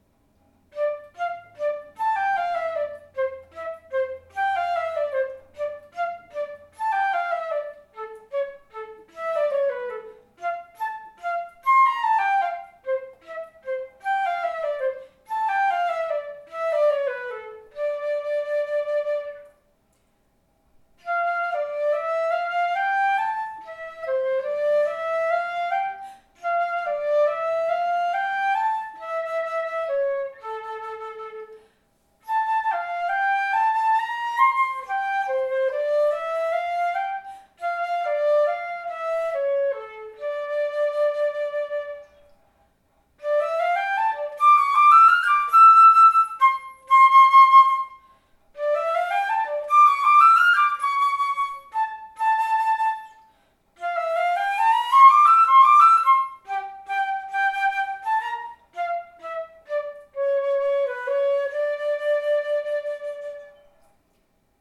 The Louis Lot flute is a silver instrument with a gold lip plate, open-hole keys, and a B foot.
As such, all the below recordings were made using the same recorder settings and player positioning (including microphone distance) within the same room.
Active:  Louis Lot flute (c. 1906)
On the other hand, the Lot’s delicate thread of tone paired nicely with the active materials, in particular during segments of eighth notes.